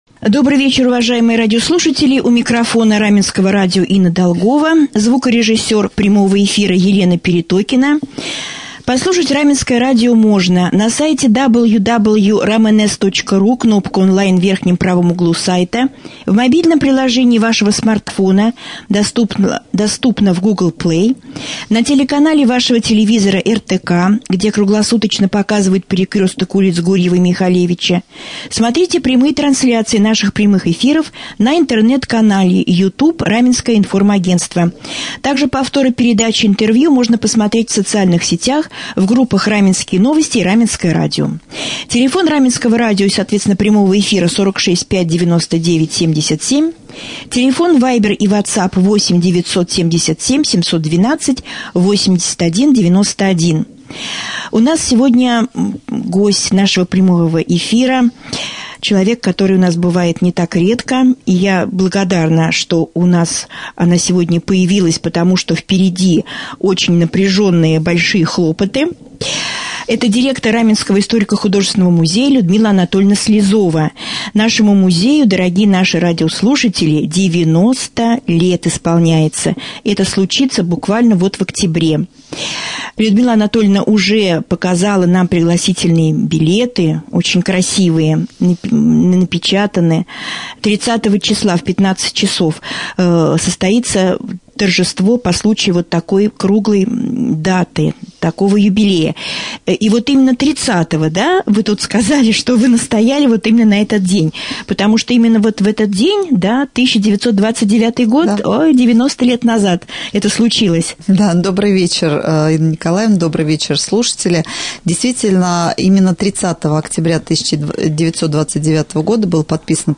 Pryamoj-efir.mp3